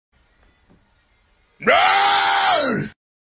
Old Yawn
old-yawn.mp3